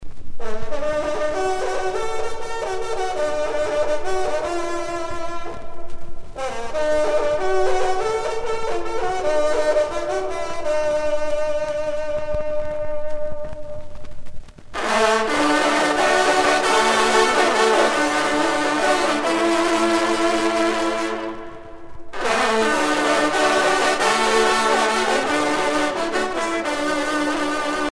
trompe - Fanfares et fantaisies de concert
circonstance : vénerie